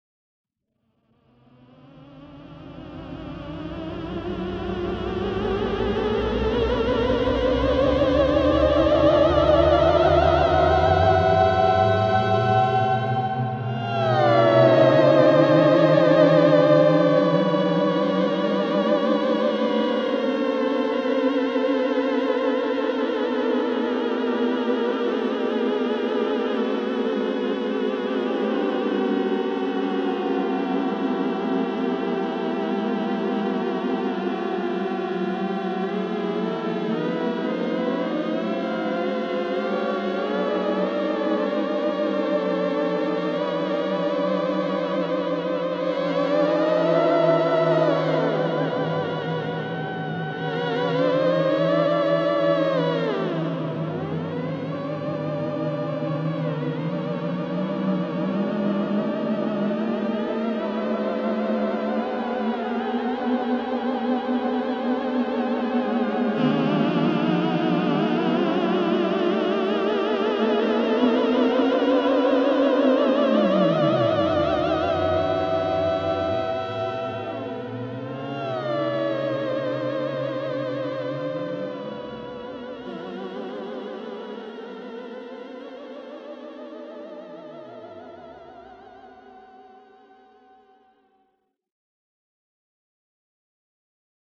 scarymusic.mp3